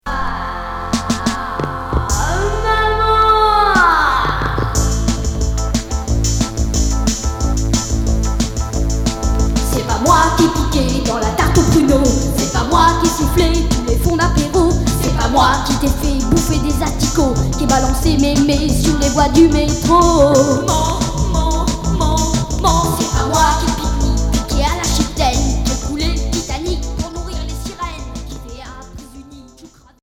Rock new-wave enfantin Unique 45t retour à l'accueil